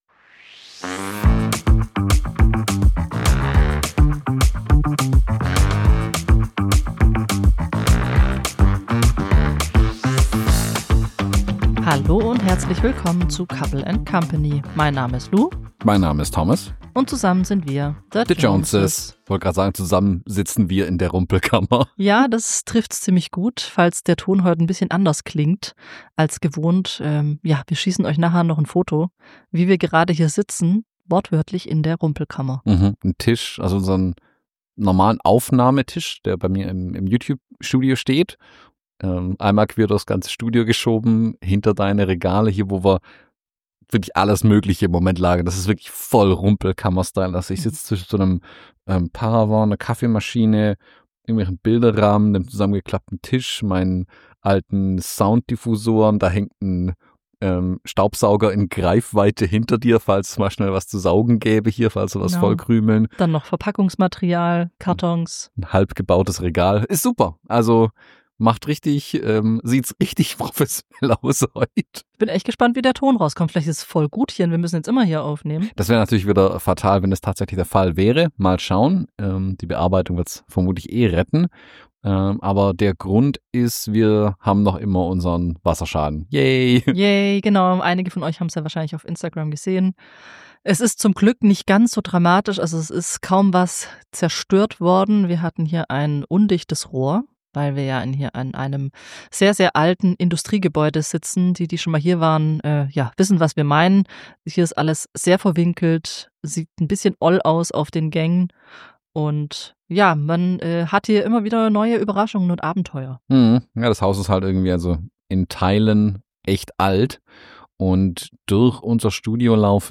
Unser Studio gleicht zurzeit einer Rumpelkammer und wir nehmen euch mit in dieses kleine Chaos.
Trotz Wasserschaden und improvisiertem Aufnahmeort plaudern wir über die Abenteuer der letzten Wochen, unseren Urlaub in Holland und technische Tücken des Alltags.